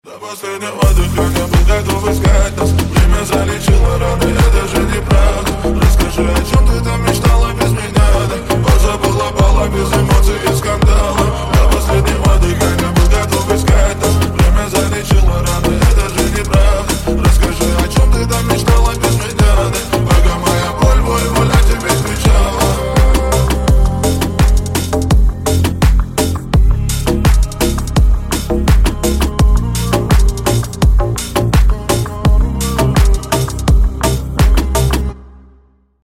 Рингтоны Ремиксы » # Поп Рингтоны